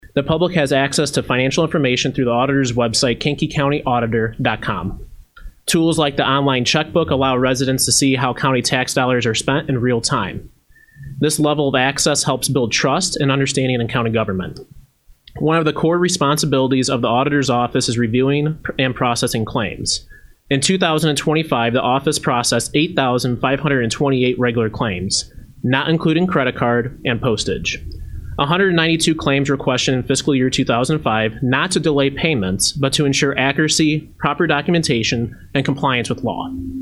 During Thursday morning’s meeting of the Kankakee County Board’s Finance Committee, County Auditor Colton Ekhoff gave the committee a rundown of some of the activities from his office in 2025.